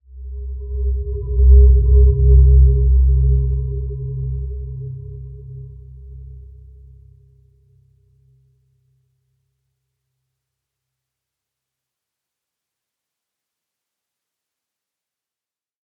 Dreamy-Fifths-C2-p.wav